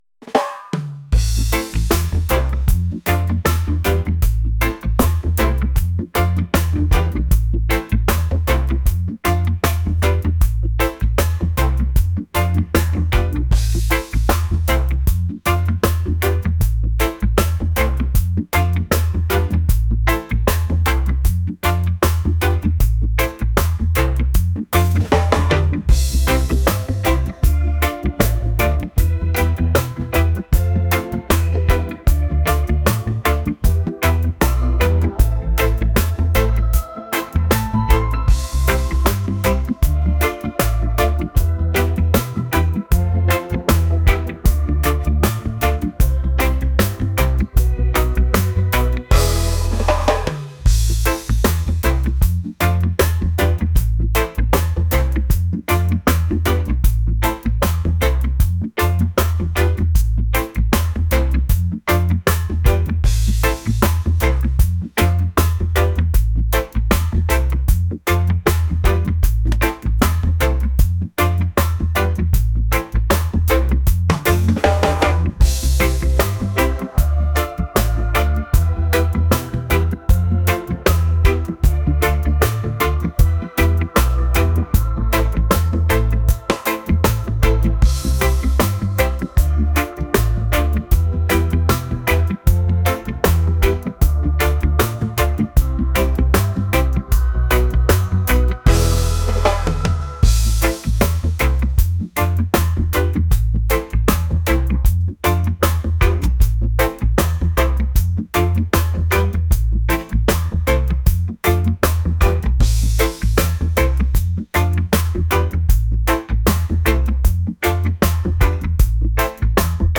reggae | acoustic | lounge